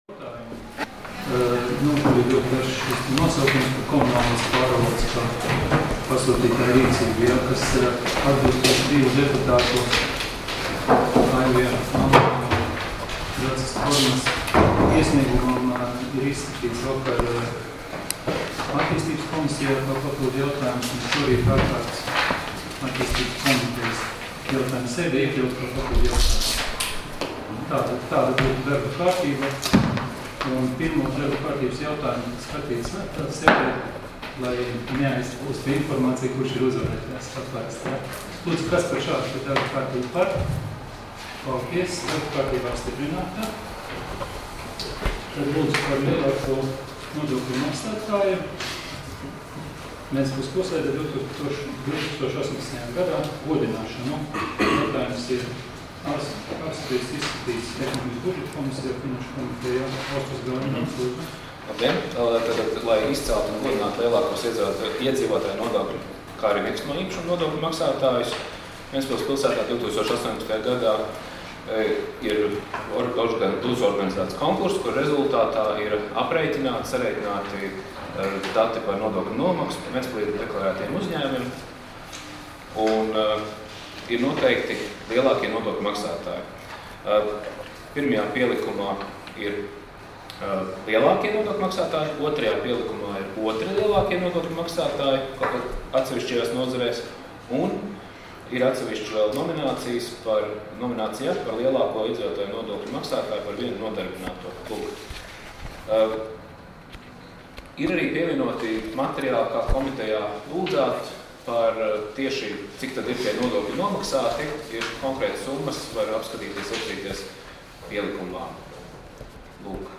Domes sēdes 14.06.2019. audioieraksts